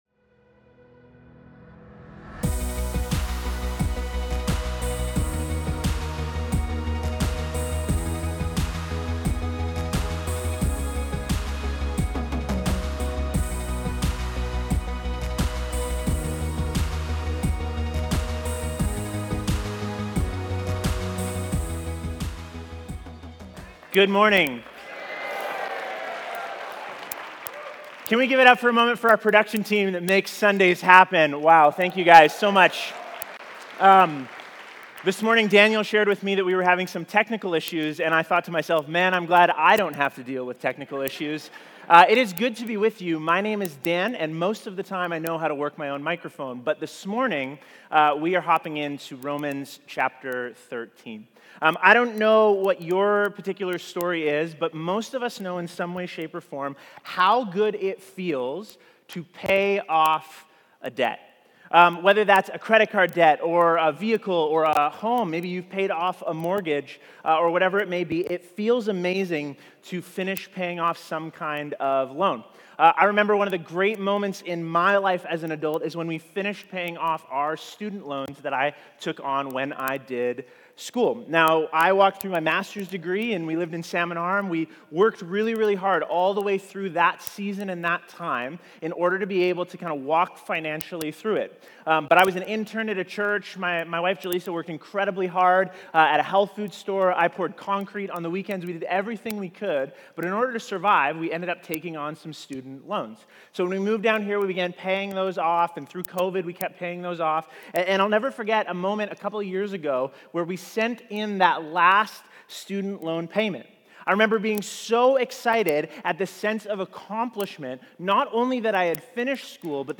Sermons | Ridge Church